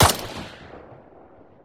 m4a1_fire.2.ogg